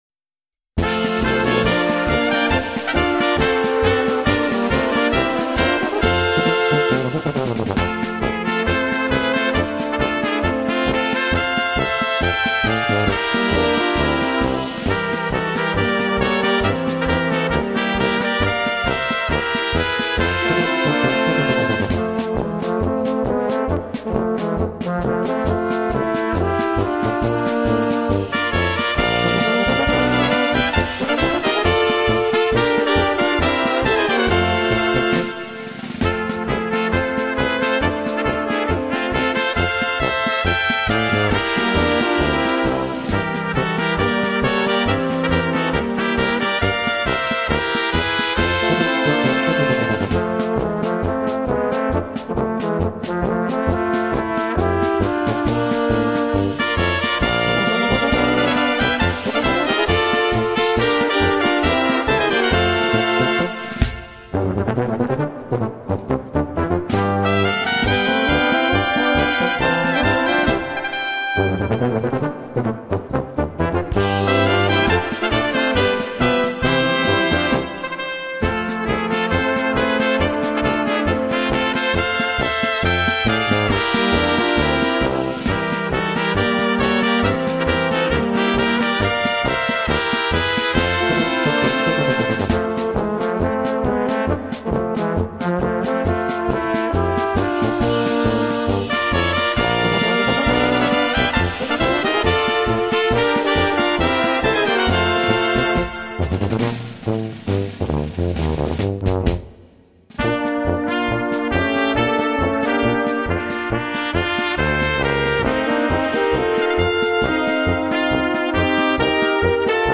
Gattung: Polka
Besetzung: Kleine Blasmusik-Besetzung
Eine schmissige Polka